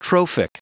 Prononciation du mot trophic en anglais (fichier audio)
trophic.wav